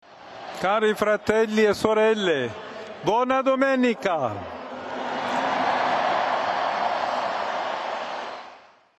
The Pontiff appeared in front of crowds in St Peter’s Square, where he delivered the Regina Coeli.